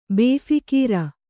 ♪ bēphikīra